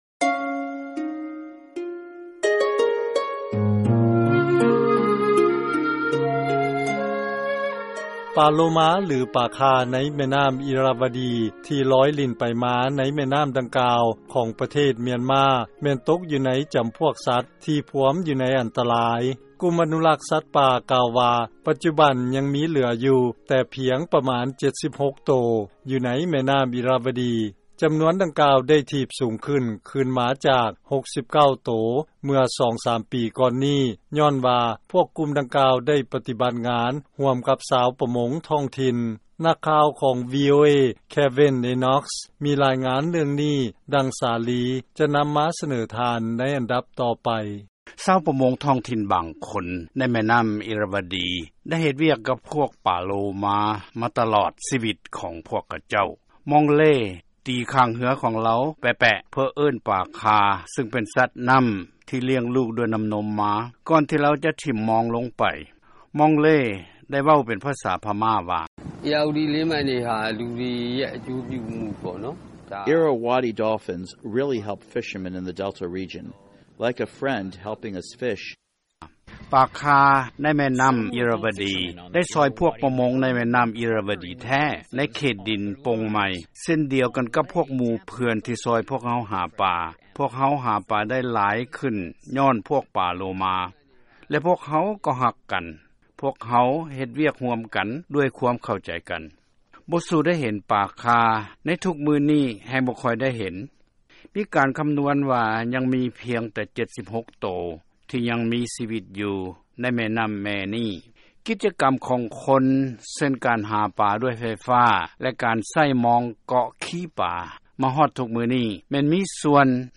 ເຊີນຟັງລາຍງານກ່ຽວກັບການອະນຸລັກປາໂລມາໃນແມ່ນໍ້າອິຣະວະດີ